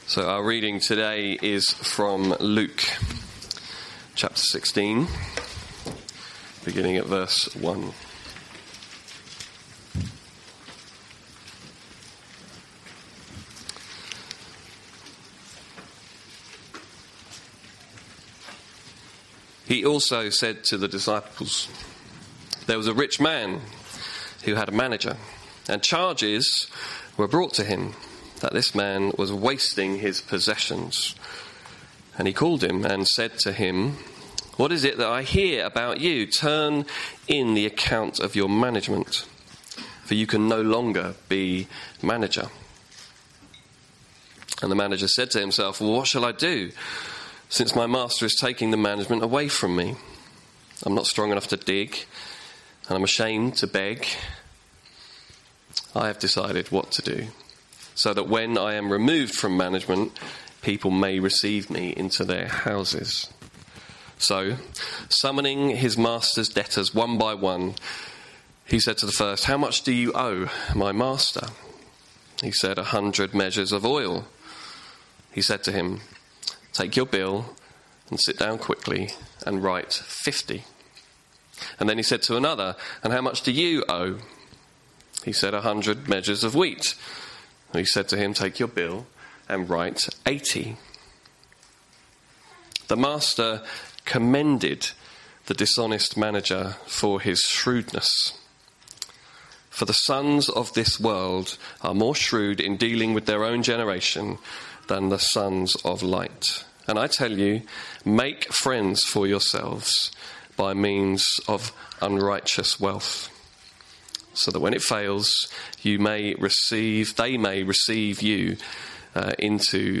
Luke 16:1-15 In this sermon